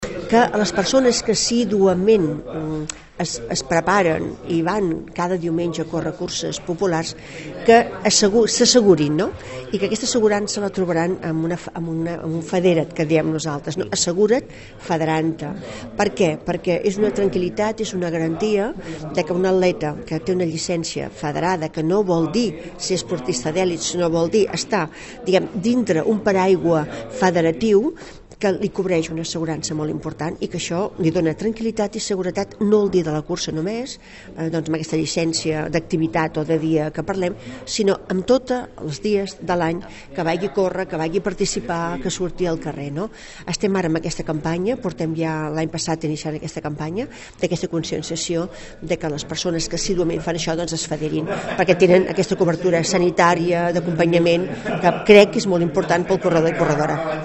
Presentada aquesta tarda a la Casa de la Vila la 26a edició de la Cursa de Martorell, una cita coorganitzada per l’Ajuntament i el Martorell Atlètic Club (MAC).